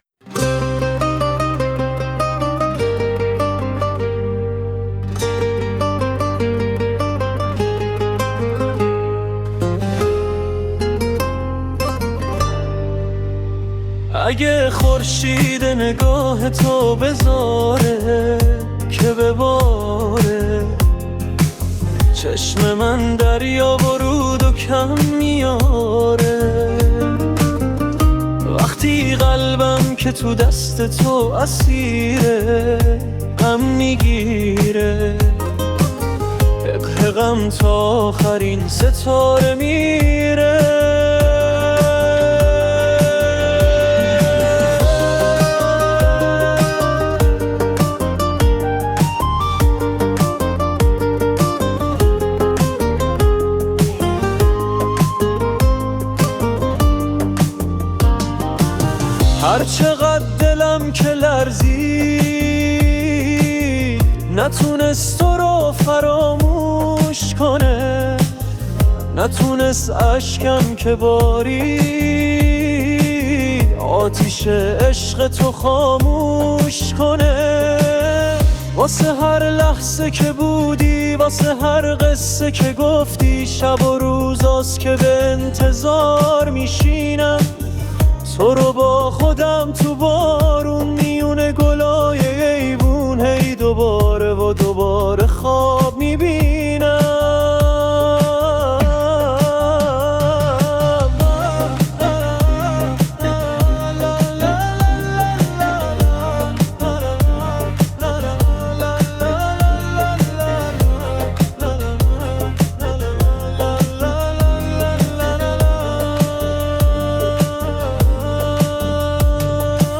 خواننده هوش مصنوعی